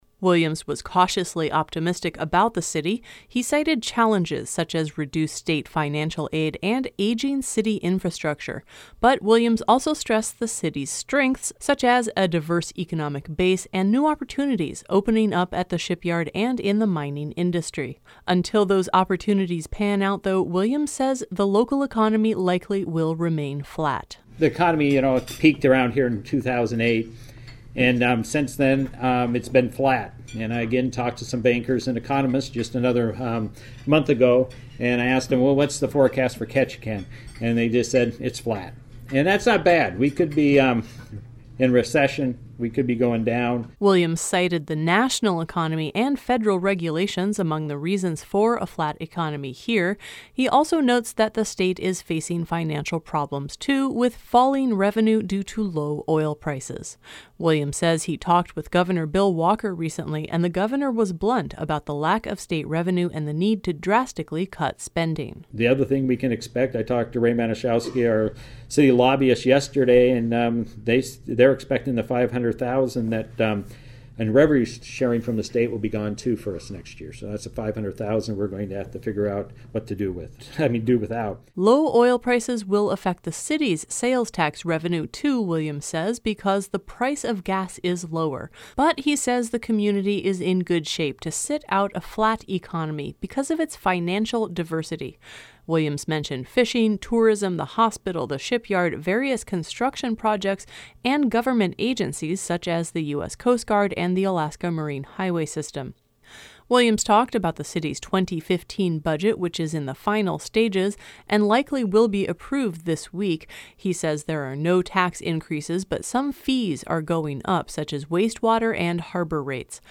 City Mayor Lew Williams III speaks to the Chamber of Commerce.
City of Ketchikan Mayor Lew Williams III gave his annual State of the City address Wednesday at the last Ketchikan Chamber of Commerce lunch of the year.